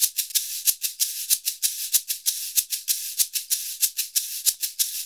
Index of /90_sSampleCDs/USB Soundscan vol.56 - Modern Percussion Loops [AKAI] 1CD/Partition B/07-SHAKER094